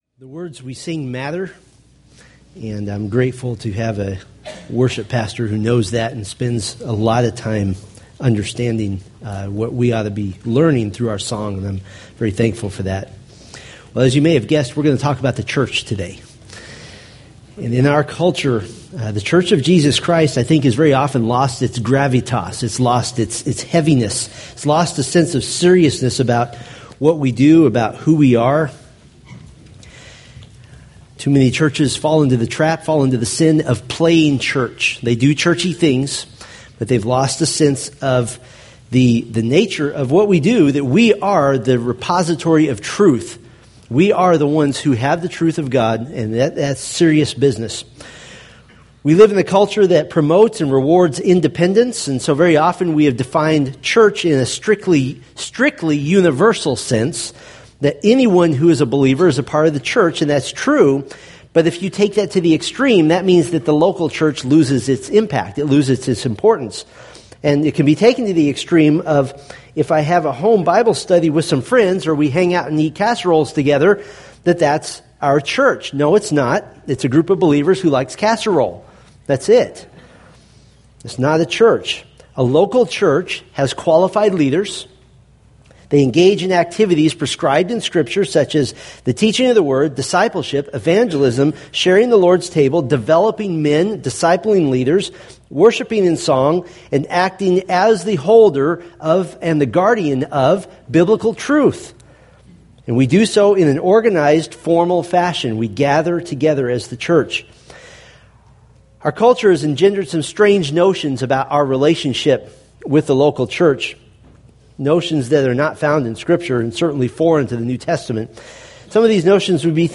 Romans Sermon Series